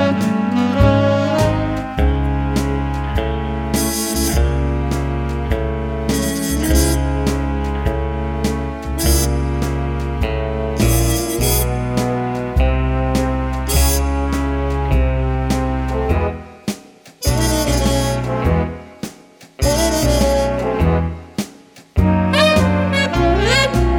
No Backing Vocals Crooners 2:49 Buy £1.50